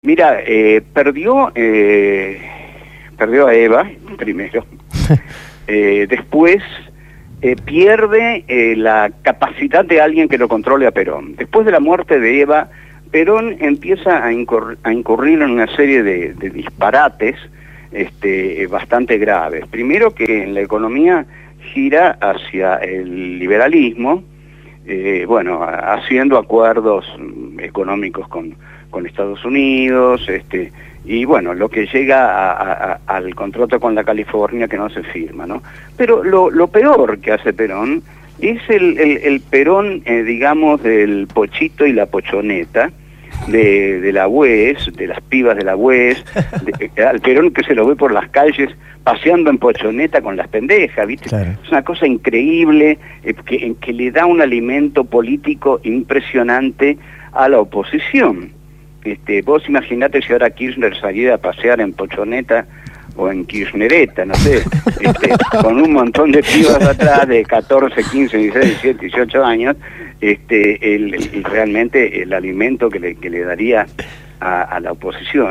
En «Por el Chori y por la Torta» (Domingos, de 22:00 a 23:00) el filósofo José Pablo Feimann fue entrevistado por nuestros compañeros de la Agrupación «Putos Peronistas«.